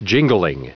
Prononciation du mot jingling en anglais (fichier audio)